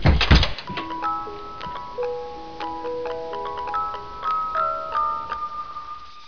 mbox_open.wav